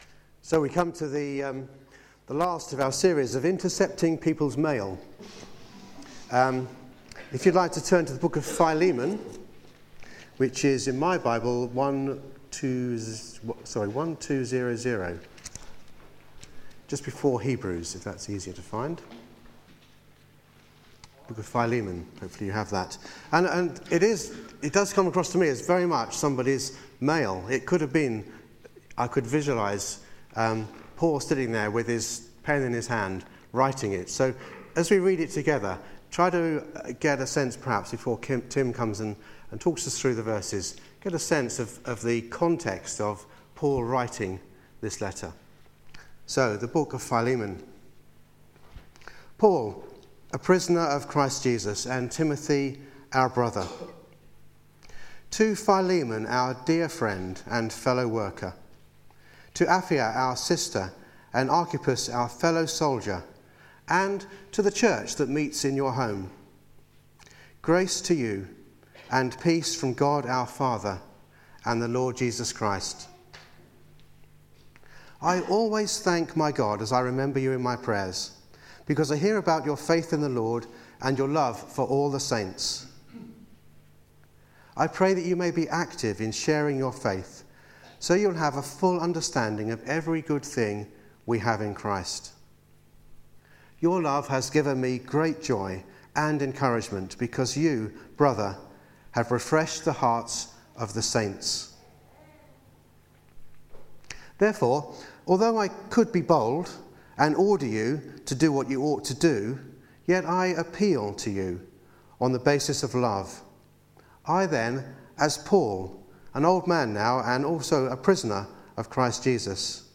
Theme: Paul's Appeal Sermon